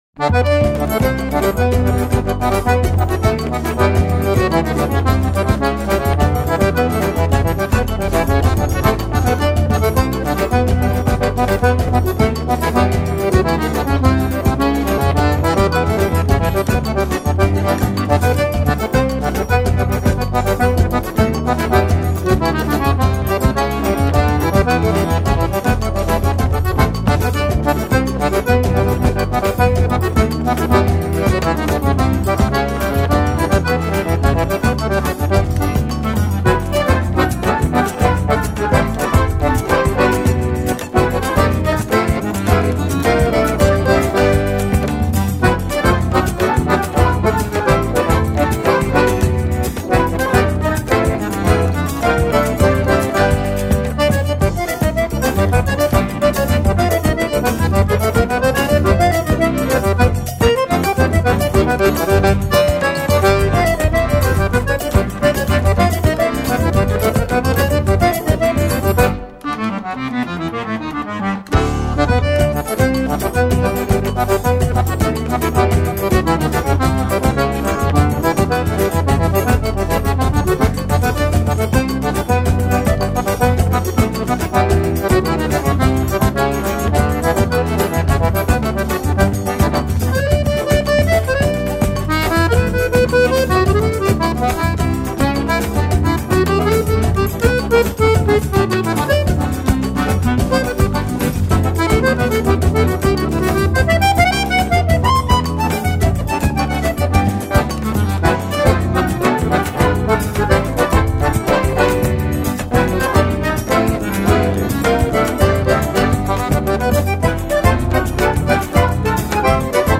Baixo Elétrico 6
Acoordeon, Teclados
Cavaquinho
Bateria
Zabumba, Triângulo, Agogo, Caxixi, Pandeiro